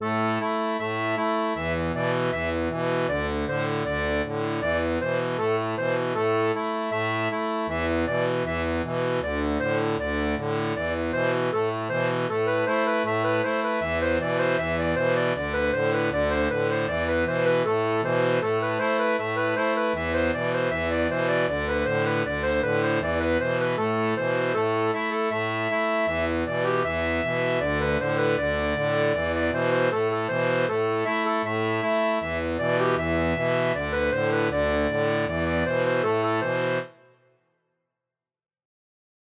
Le tout agrémenté de deux variations persos.
Hip-Hop